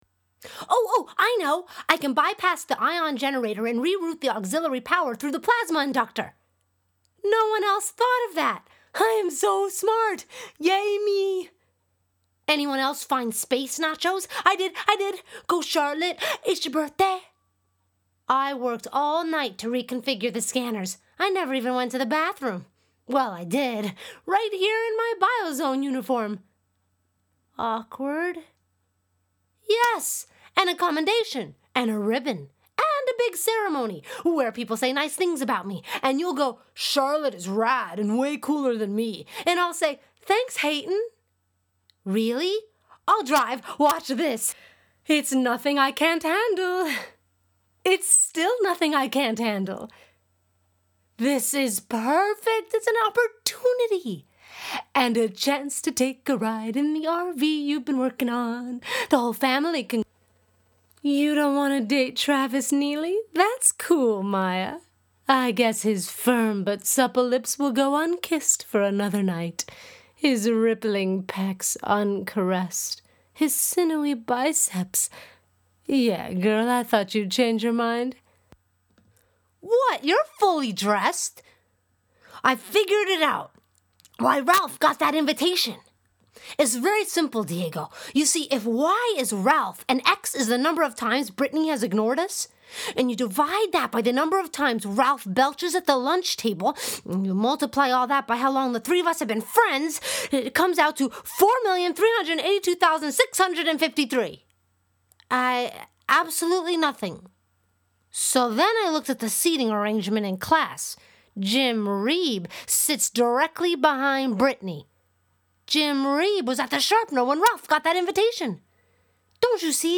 Female
Yng Adult (18-29), Adult (30-50)
Quirky and unique.
Character / Cartoon
Animation Characters